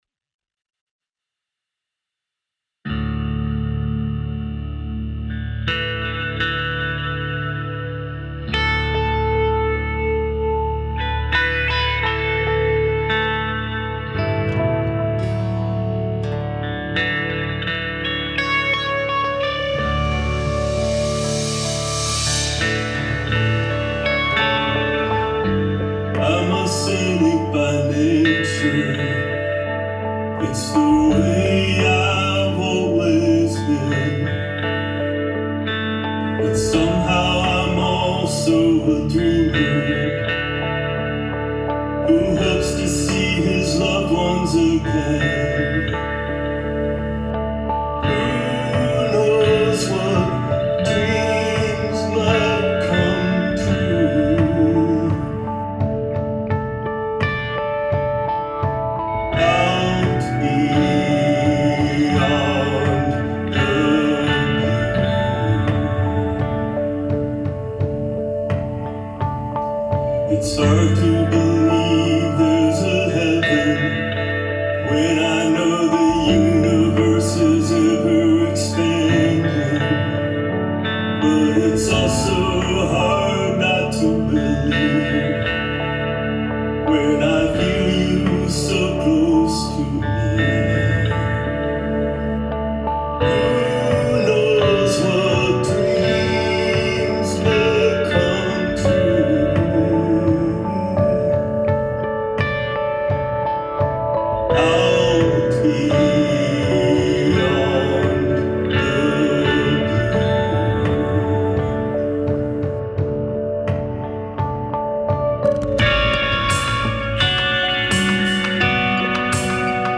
ALT FOLK
Very interesting dreamy, contemplative tone to it.
The guitarwork is absolutely beautiful.